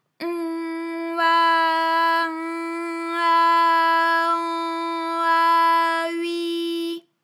ALYS-DB-001-FRA - First, previously private, UTAU French vocal library of ALYS
on_a_in_a_an_a_ui.wav